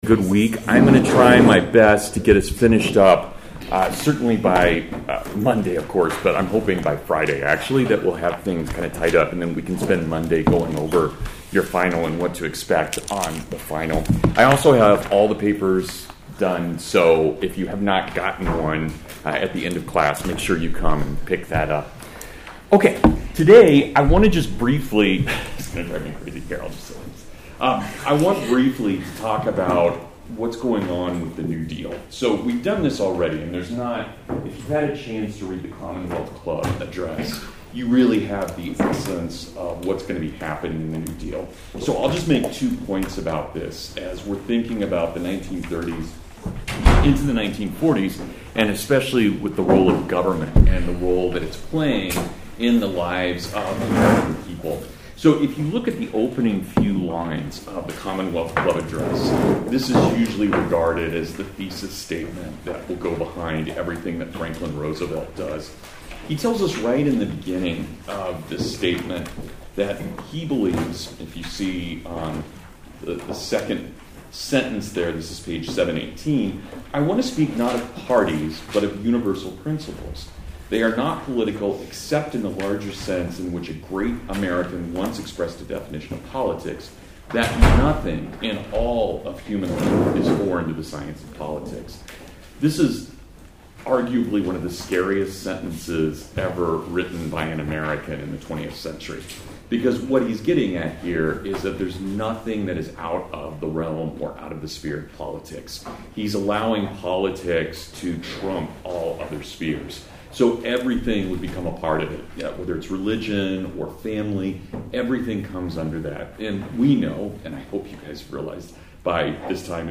The 1950s (Full Lecture)